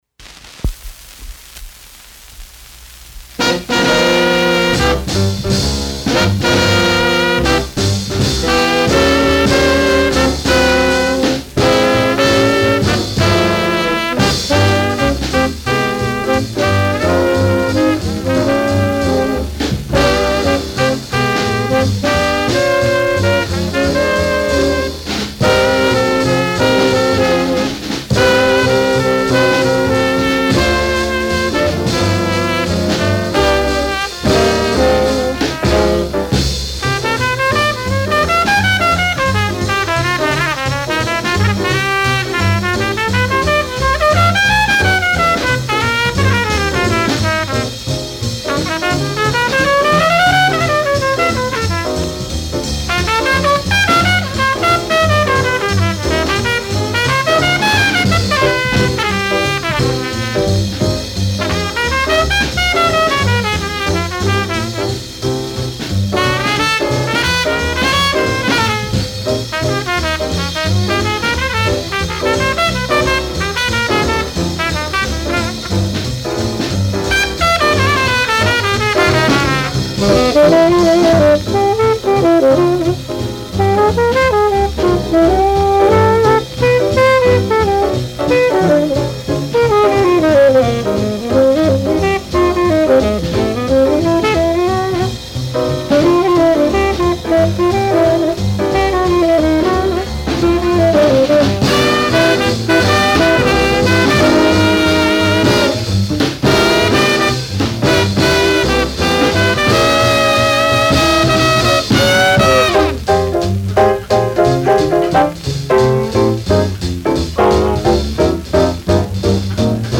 Recorded September 13, 1948 at Apex Studios NYC